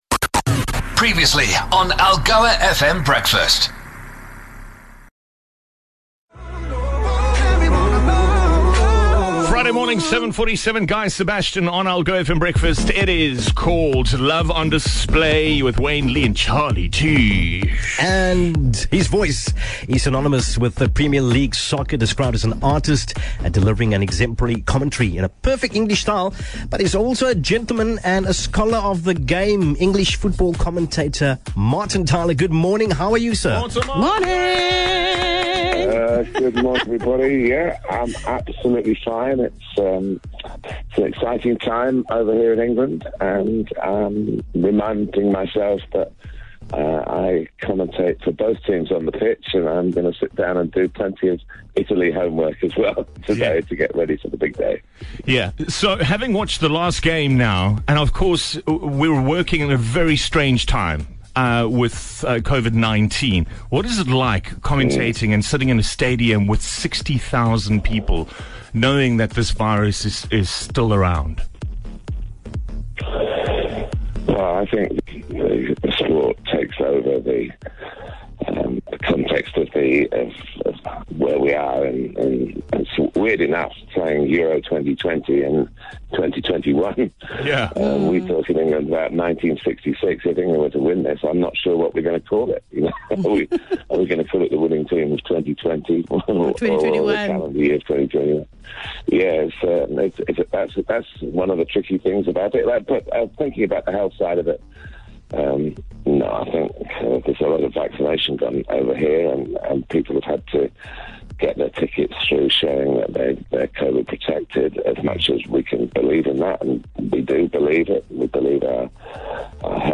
The Team put a call through to England to chat to Tyler ahead of Sunday's Euro 2020 semifinal!